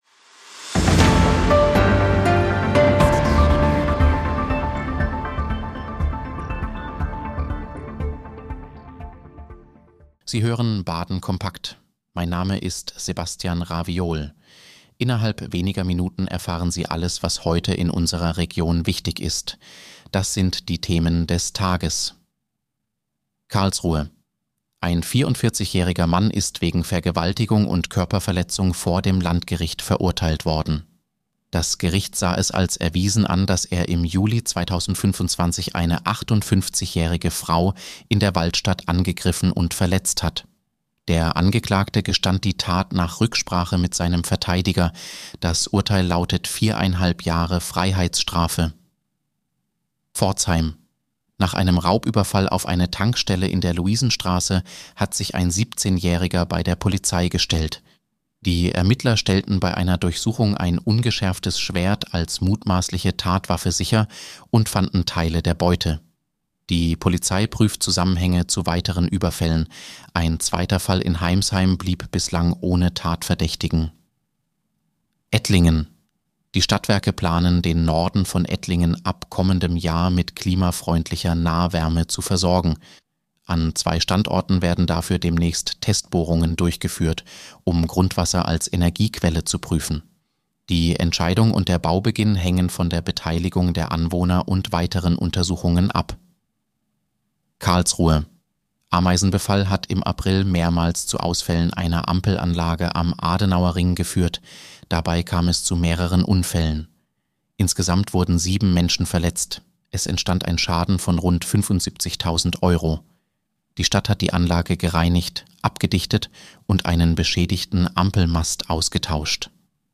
Nachrichtenüberblick: Urteil wegen Vergewaltigung am Landgericht Karlsruhe